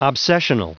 Prononciation du mot : obsessional
obsessional.wav